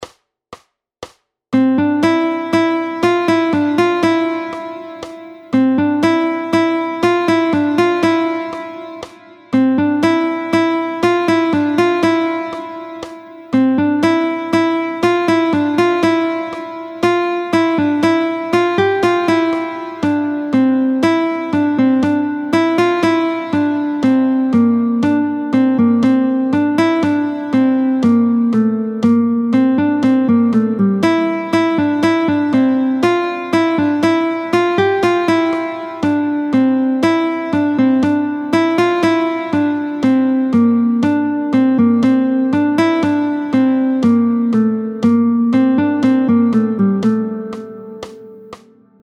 √ برای ساز گیتار | سطح آسان
همراه 3 فایل صوتی برای تمرین هنرجویان